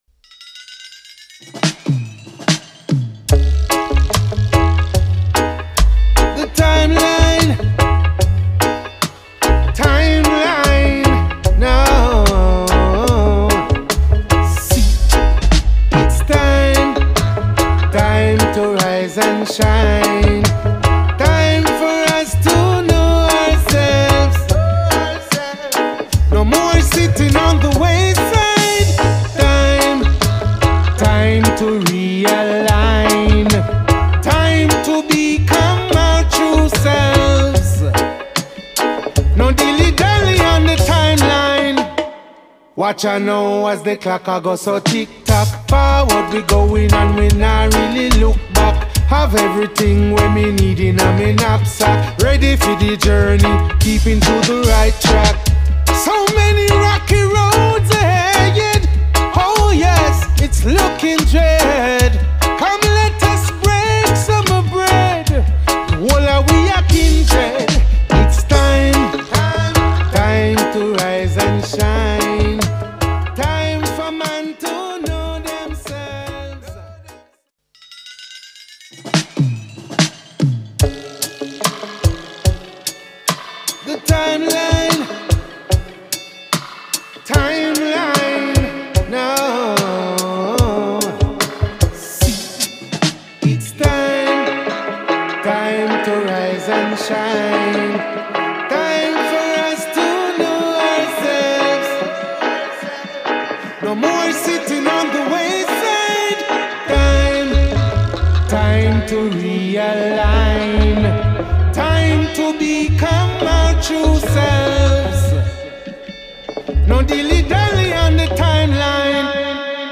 a 100% roots single
a key figure in the UK conscious reggae scene.
bass line